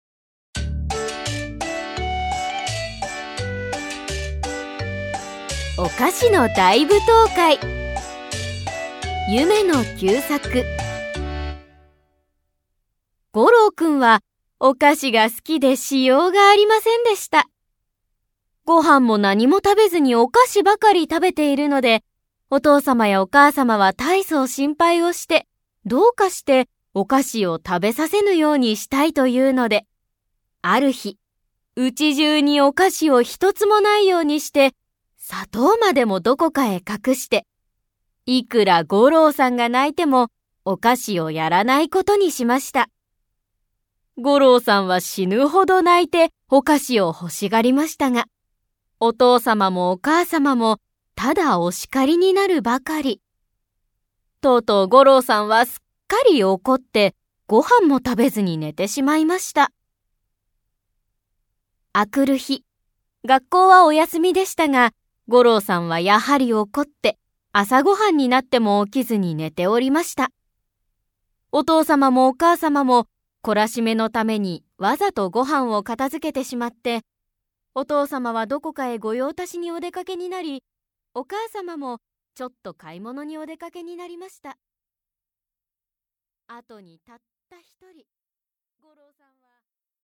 [オーディオブック] 夢野久作「お菓子の大舞踏会」